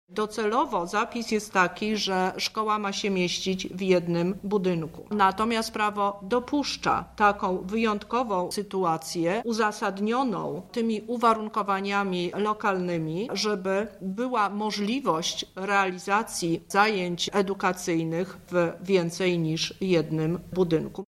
O szczegółach mówi Teresa Misiuk – lubelska kurator oświaty